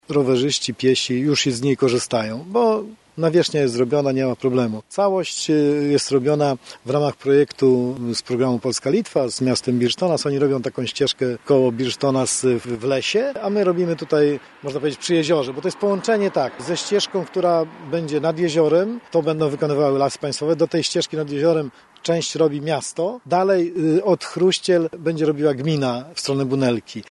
O projekcie, który jest już na ukończeniu, mówi Marek Chojnowski, starosta ełcki.